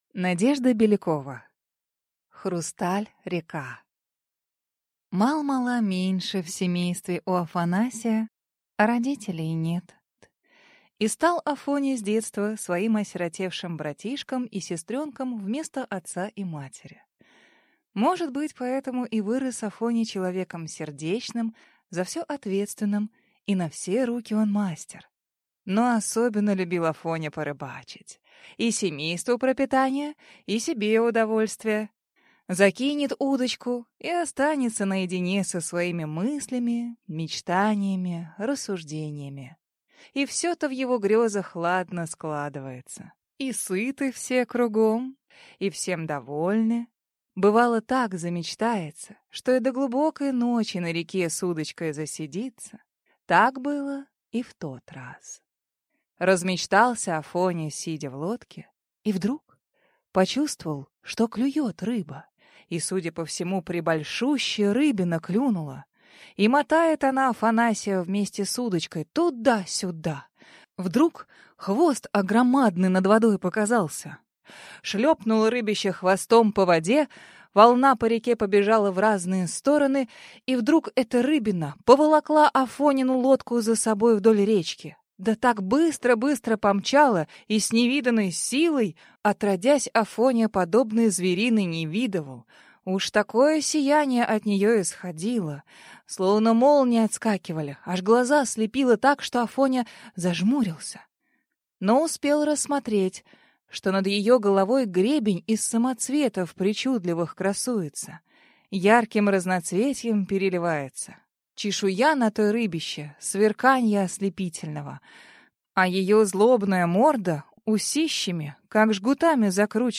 Аудиокнига Хрусталь-река | Библиотека аудиокниг